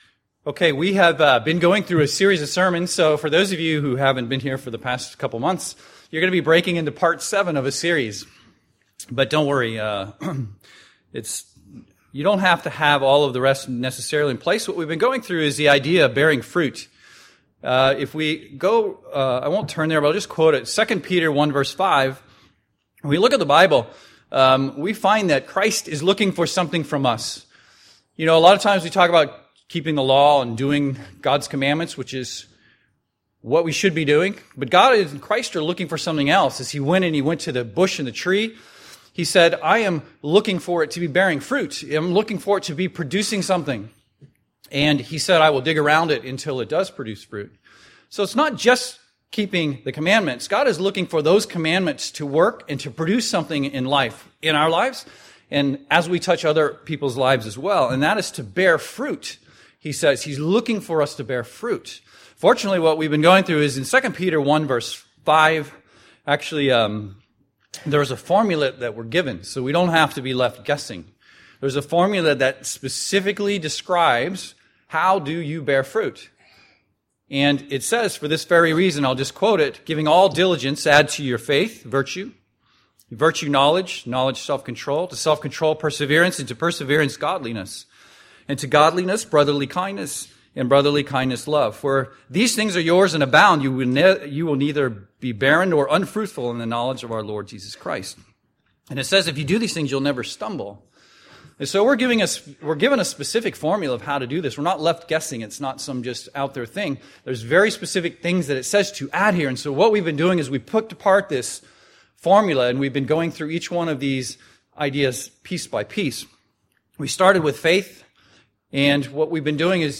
There is a formula outlined in 2 Peter 1:5 of exactly how to bear fruit. During this sermon we will learn 3 things about Godliness: What exactly does godliness mean?
Given in Seattle, WA